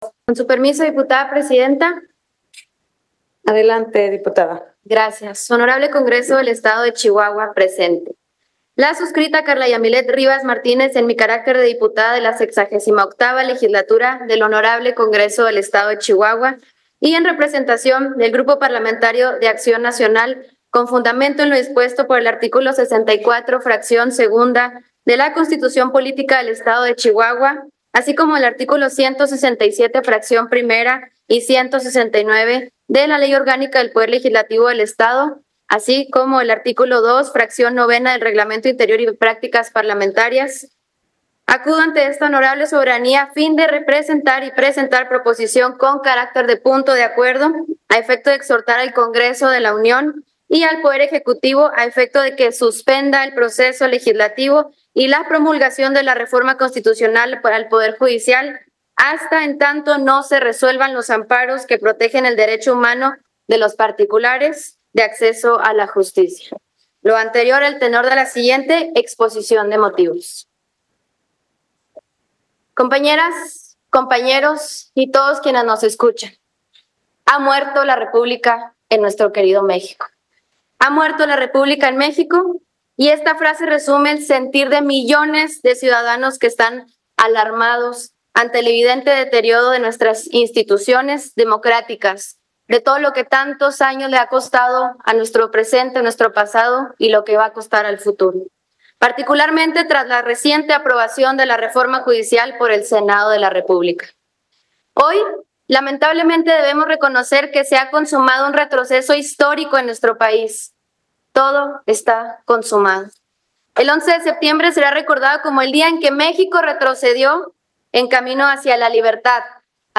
La diputada Carla Rivas, a nombre del Grupo Parlamentario del PAN, expresó su profunda preocupación tras la aprobación de la Reforma Judicial en el Senado, advirtiendo sobre los riesgos de una concentración peligrosa de poder en manos de un solo partido.